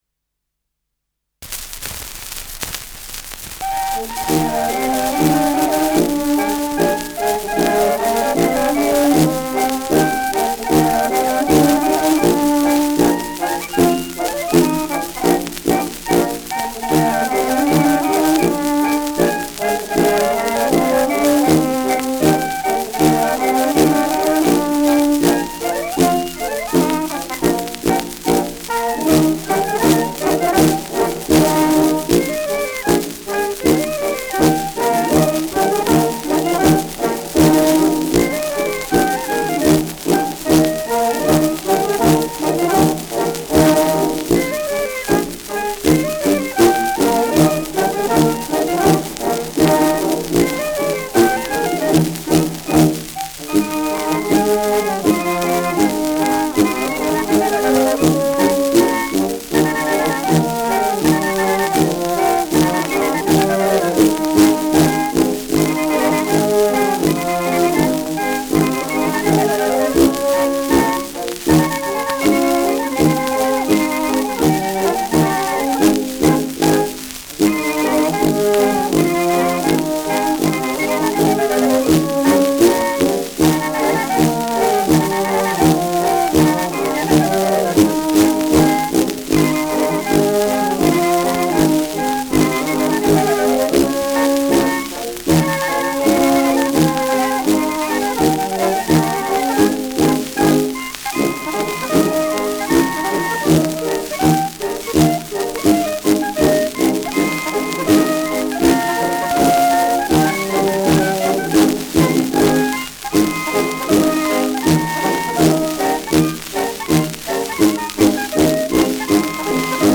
Schellackplatte
präsentes Rauschen : Knistern